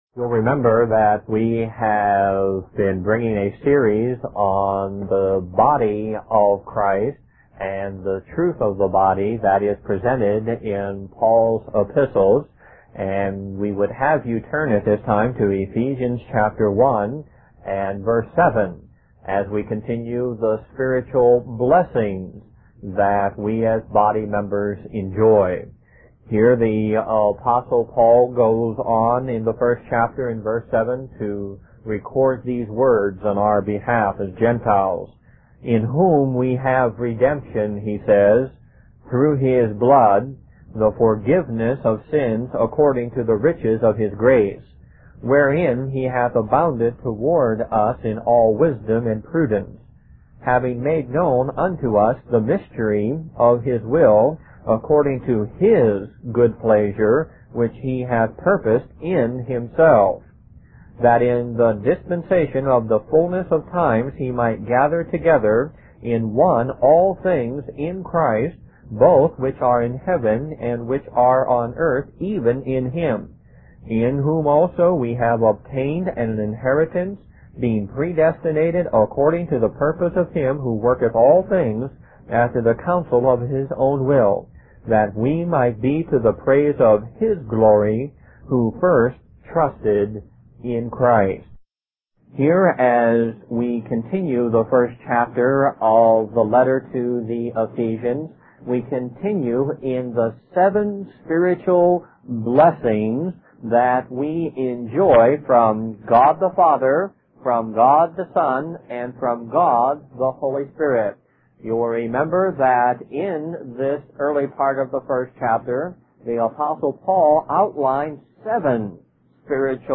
Lesson 3: Redemption Through His Blood